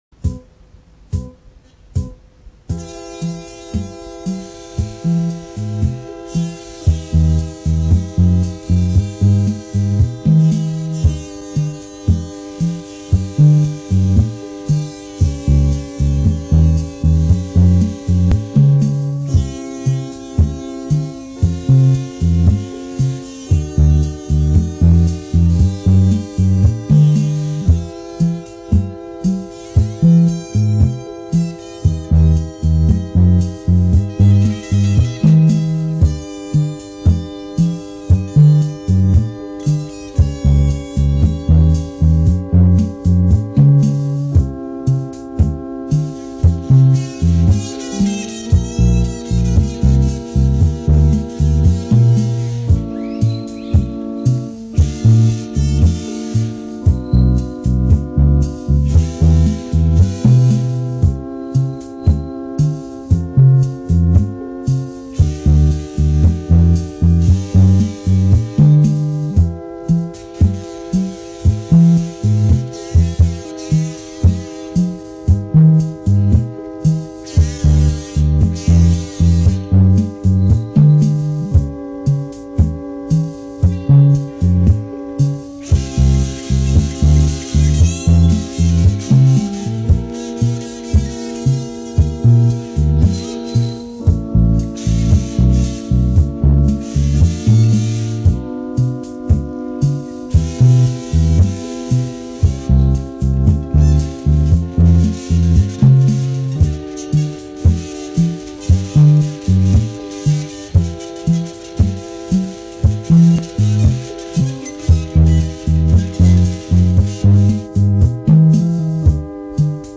Son frère faisait de la musique seul dans sa chambre et enregistrait ses compositions sur cassette.